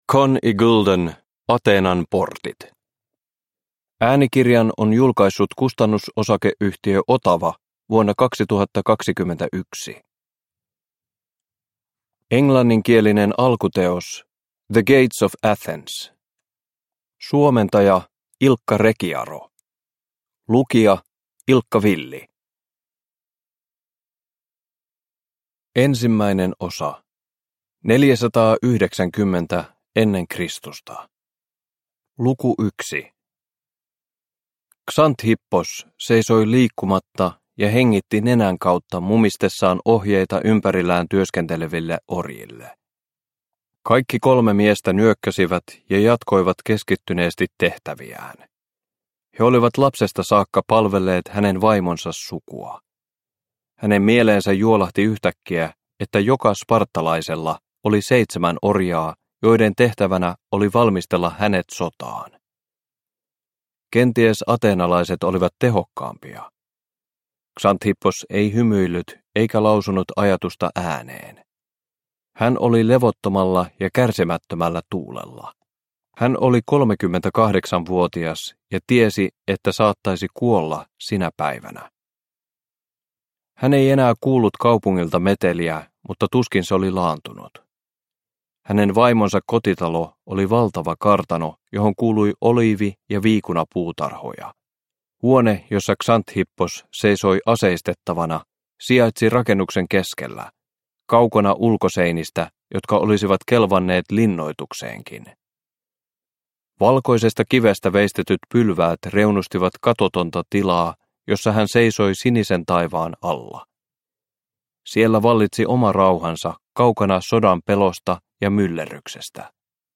Ateenan portit – Ljudbok – Laddas ner